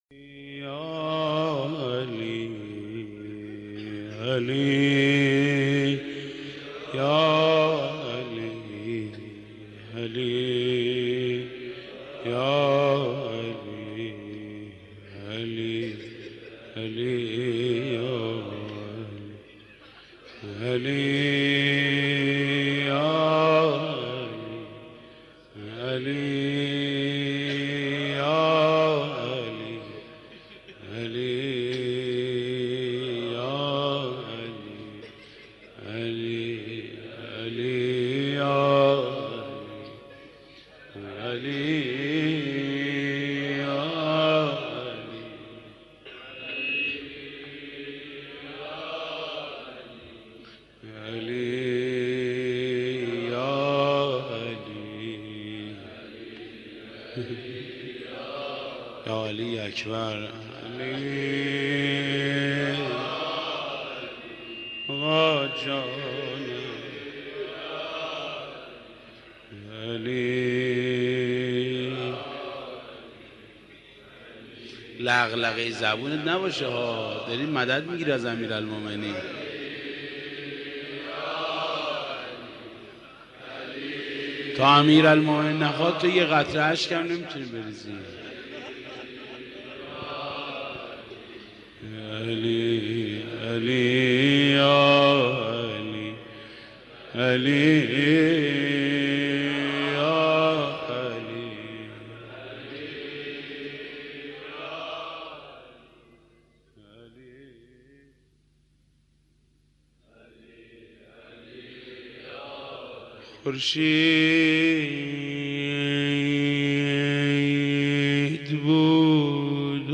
مداح
مناسبت : شب هشتم محرم
مداح : محمود کریمی